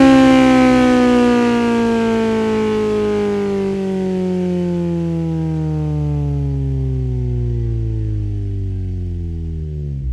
rr3-assets/files/.depot/audio/Vehicles/i4_05/i4_05_decel.wav
i4_05_decel.wav